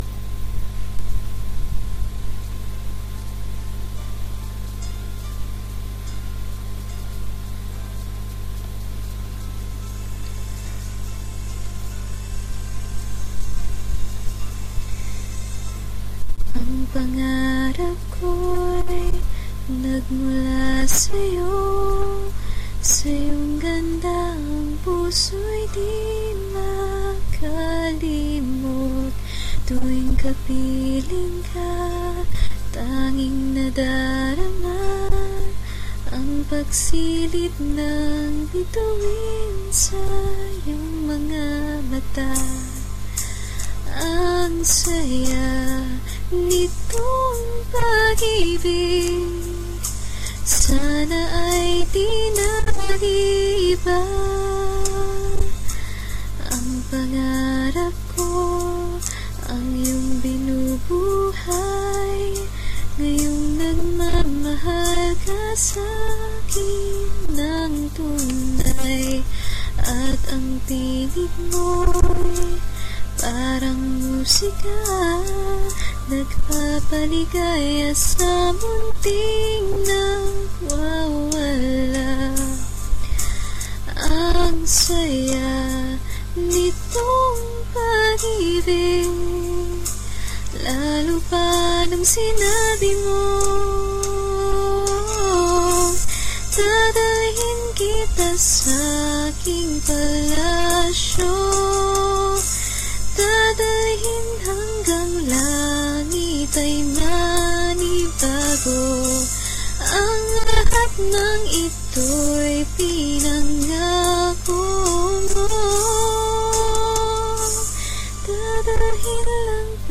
Tried to sing a childhood song.